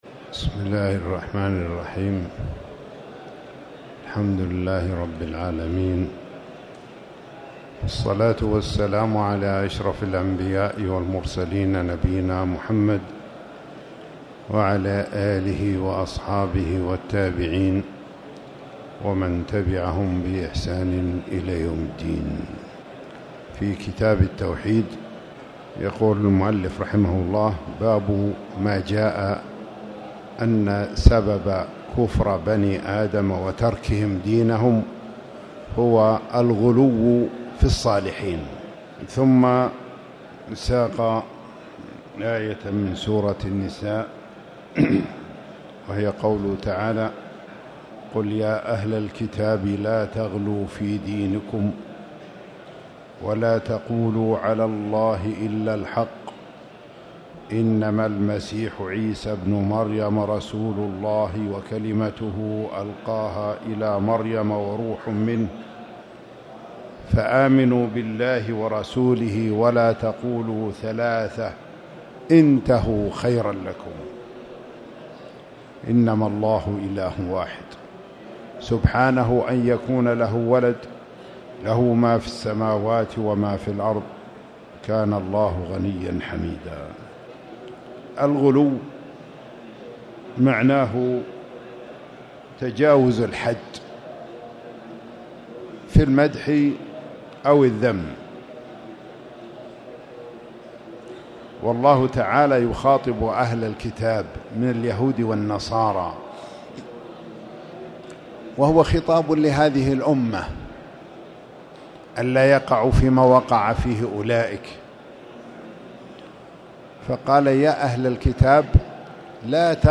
تاريخ النشر ١٤ رمضان ١٤٣٧ هـ المكان: المسجد الحرام الشيخ